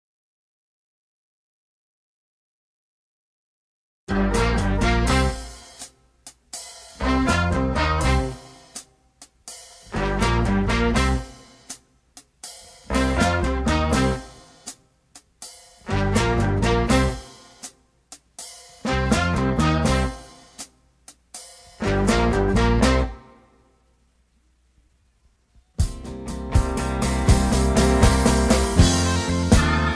rock and roll, rock, country music, backing tracks